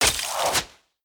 Ice Throw 1.ogg